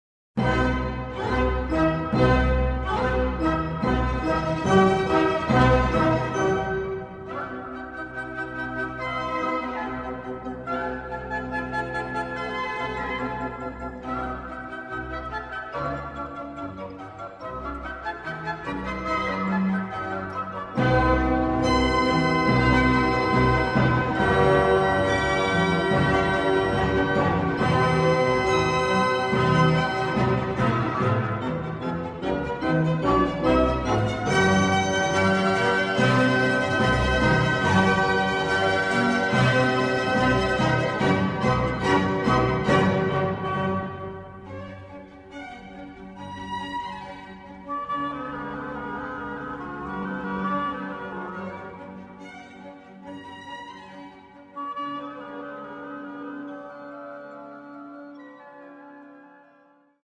C major
organ